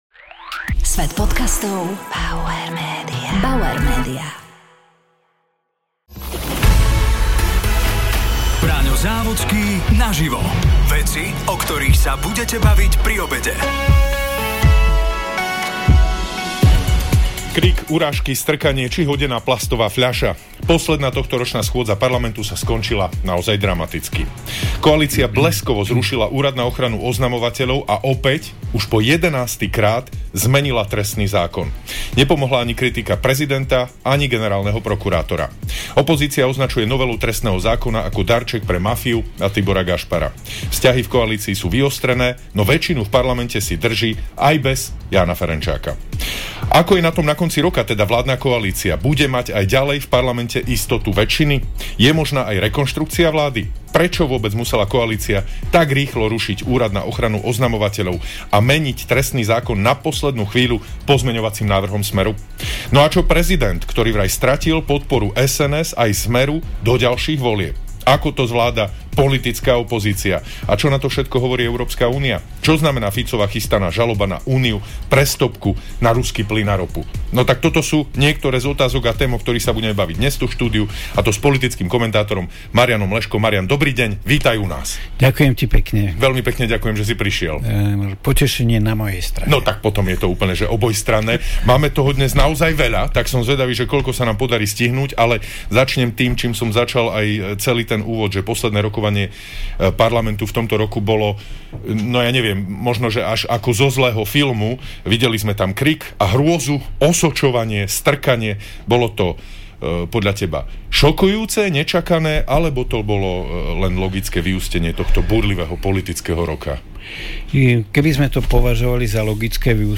Braňo Závodský sa rozprával s politickým komentátorom Mariánom Leškom.